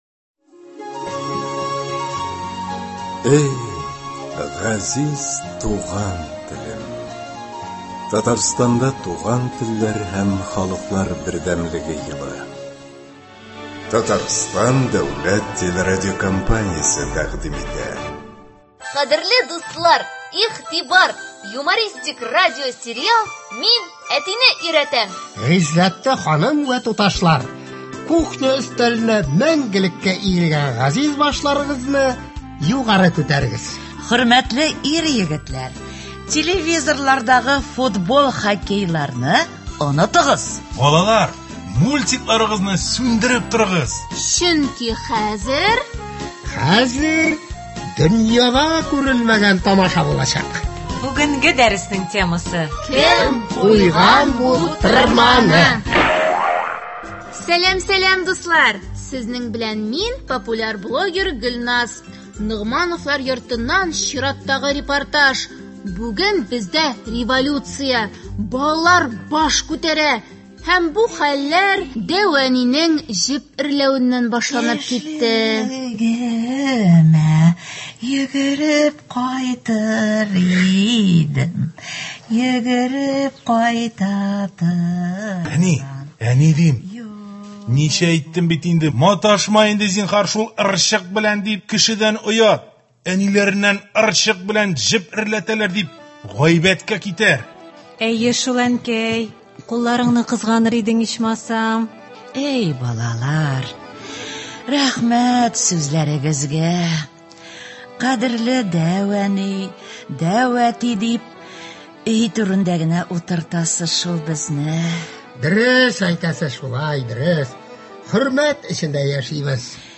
Ул – “Мин әтине өйрәтәм” дип исемләнгән радиосериал. Кыска метражлы әлеге радиоспектакльләрдә туган телебезне бозып сөйләшү көлке бер хәл итеп күрсәтелә һәм сөйләмебездәге хата-кимчелекләрдән арыну юллары бәян ителә.